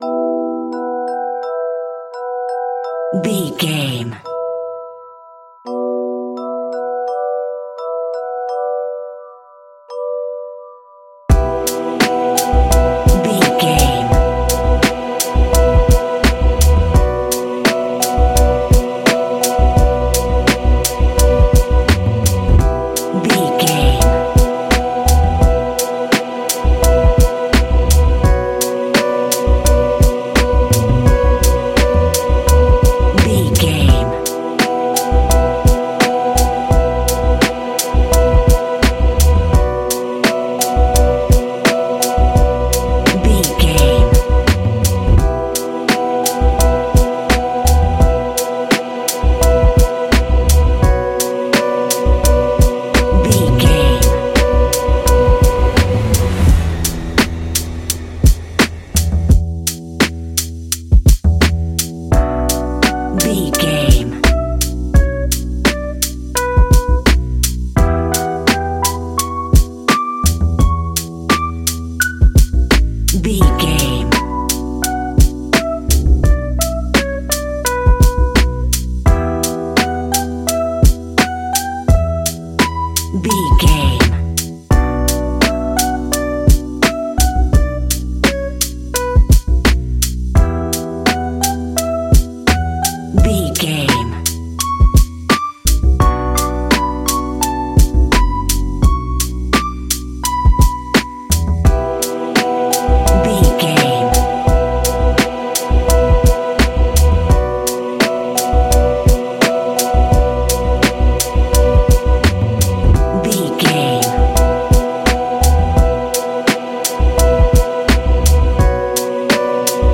Ionian/Major
B♭
laid back
Lounge
sparse
new age
chilled electronica
ambient
atmospheric